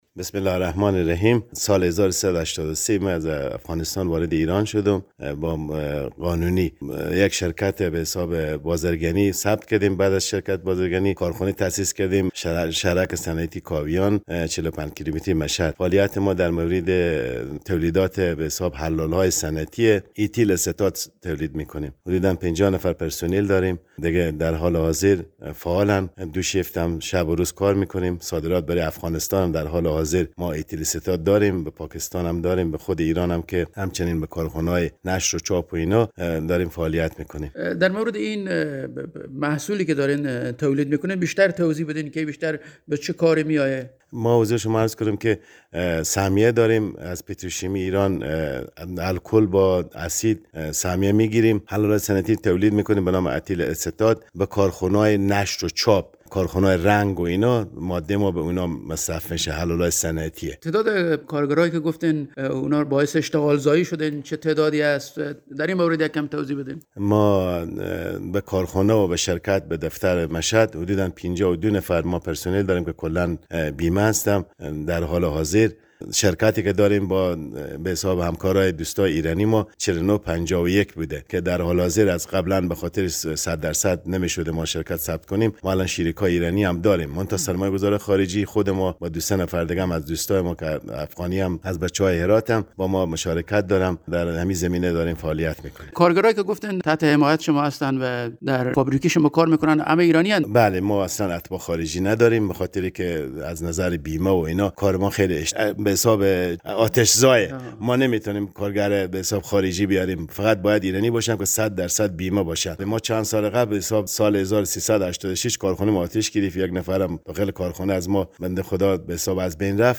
خبر / مصاحبه